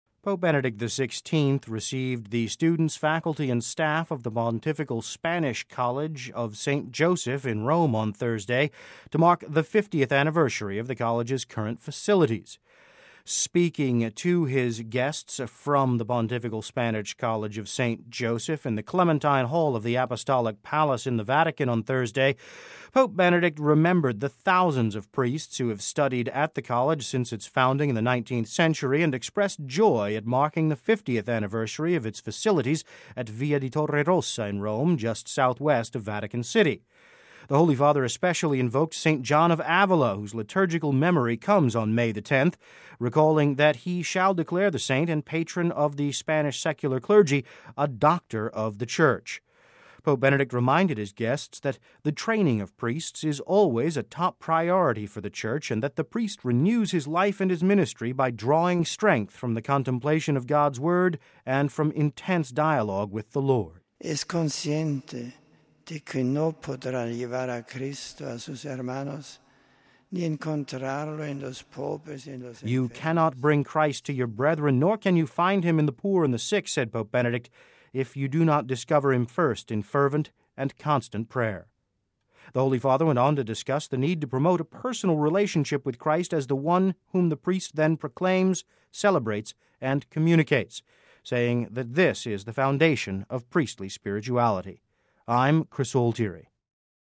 Speaking to his guests in the Clementine Hall of the Apostolic Palace in the Vatican, Pope Benedict remembered the thousands of priests who have studied at the College since its founding in the 19th century, and expressed joy at marking the fiftieth anniversary of its facilities at via di Torre Rossa in Rome, just Southwest of Vatican City.